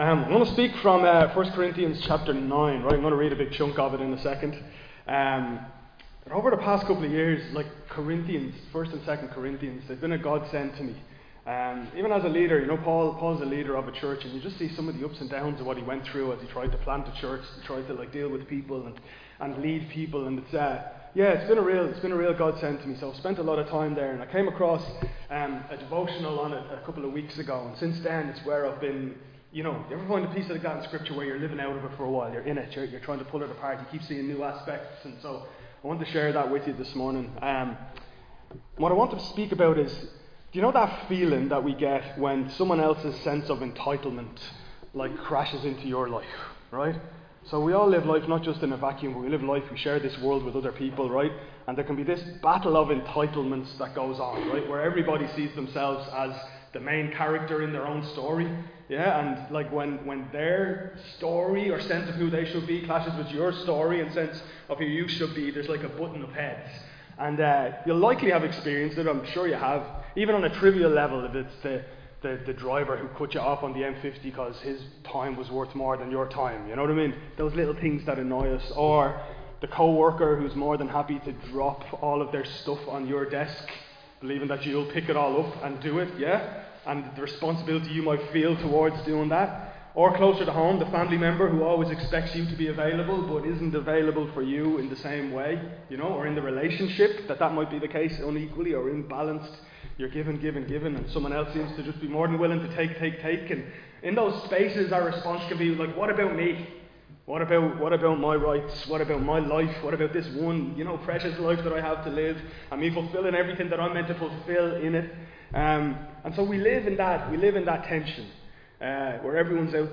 Recorded live in Liberty Church on 5 October 2025